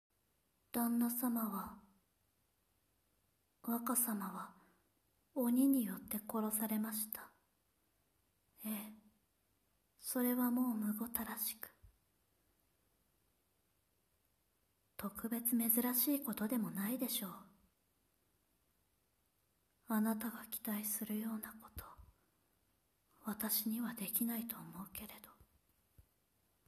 サンプルボイス 応募用